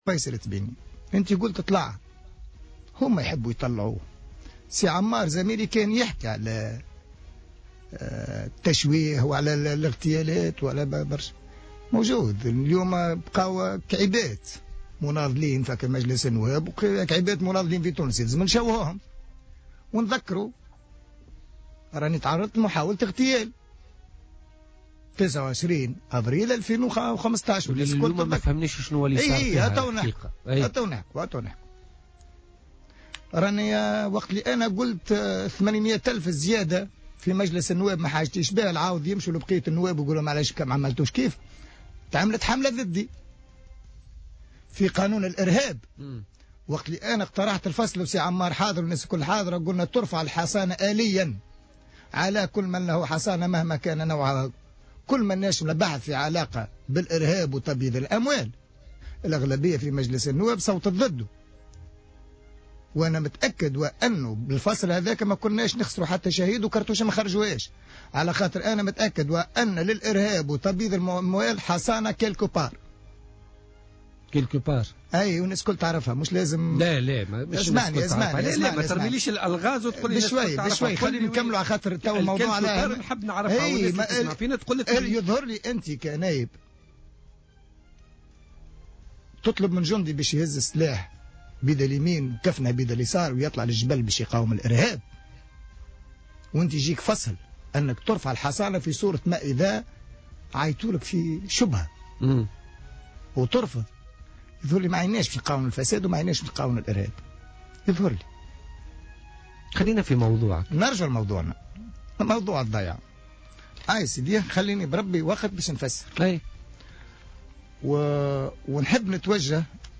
وأوضح التبيني، ضيف برنامج "بوليتيكا" اليوم أن الأرض التي اكتراها هي أرض خواص وليست على ملك الدولة كما أشيع وأنه هو من قام برفع قضية بعد رصد اخلالات وطالب بإصلاح عقد الكراء".